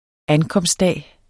Udtale [ ˈankʌmsd- ]